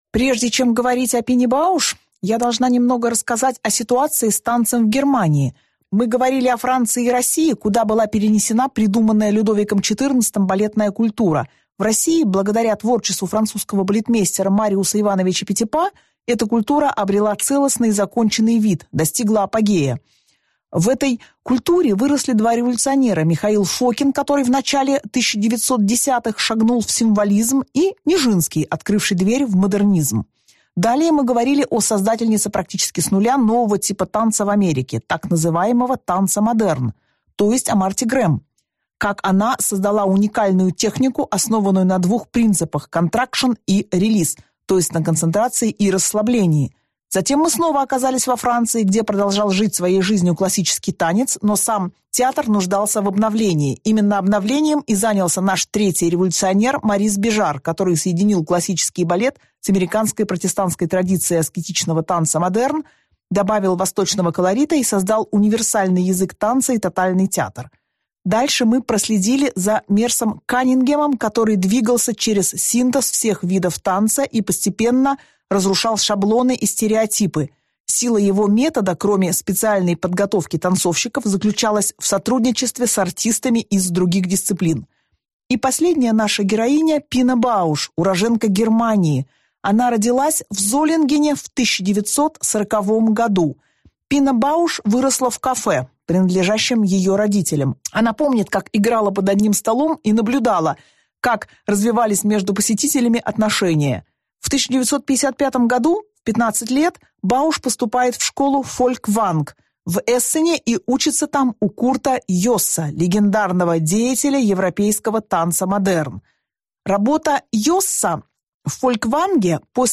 Аудиокнига Пина Бауш и немецкий «танцтеатр» | Библиотека аудиокниг